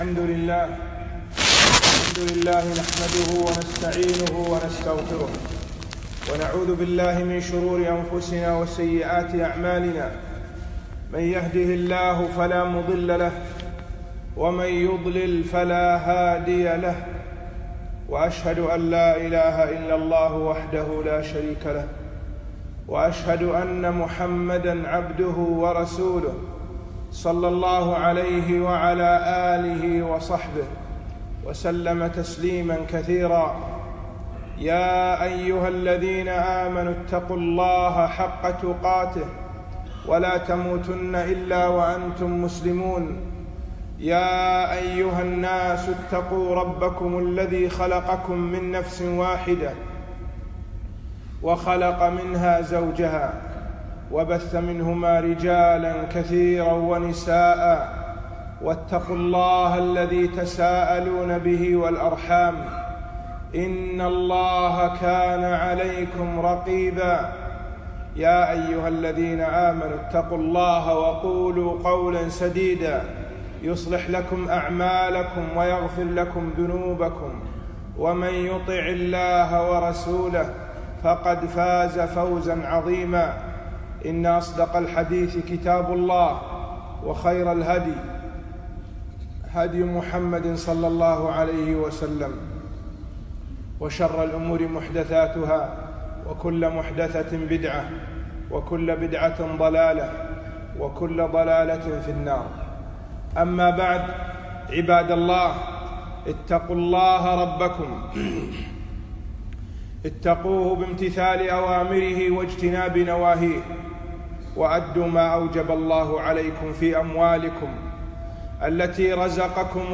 خطبة - الزكاة 1439هــ